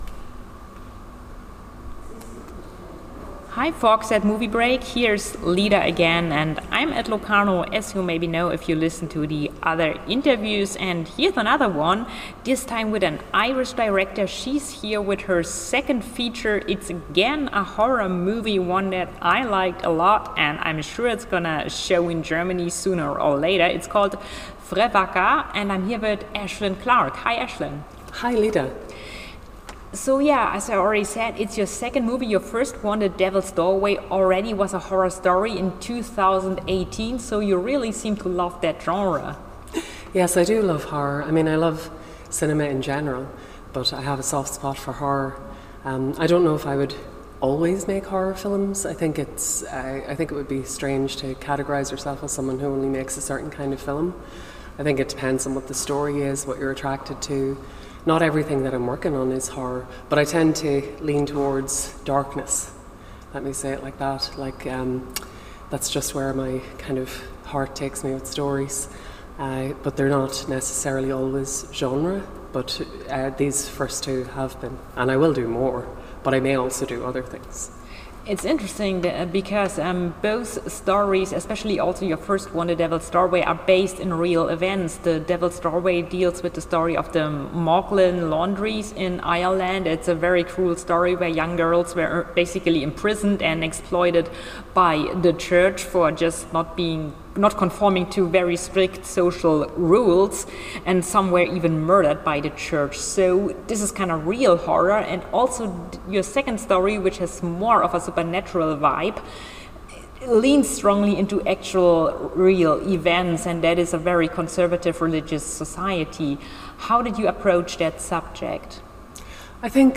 Locarno Film Festival: Interview